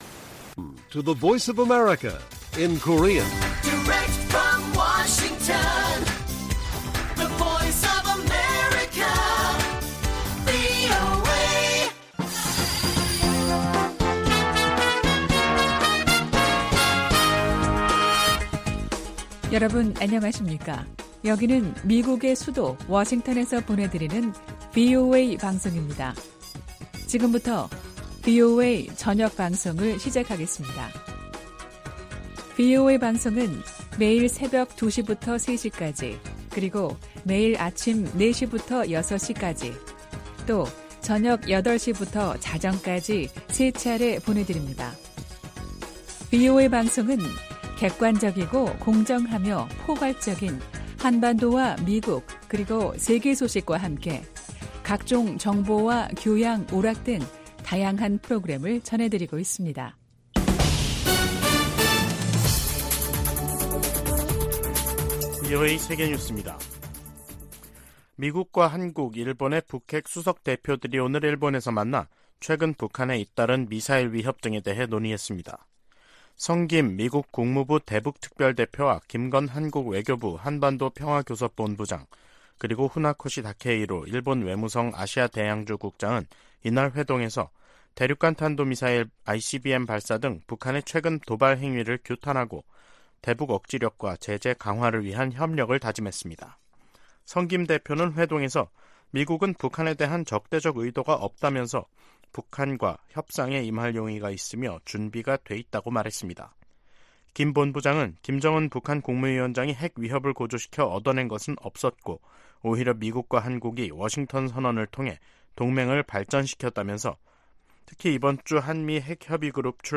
VOA 한국어 간판 뉴스 프로그램 '뉴스 투데이', 2023년 7월 20일 1부 방송입니다. 미국과 한국, 일본 정상회의가 다음달 캠프데이비드에서 열립니다.